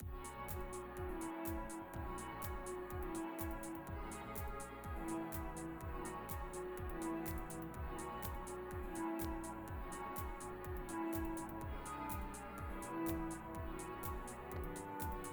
Here’s a second null test, with different audio!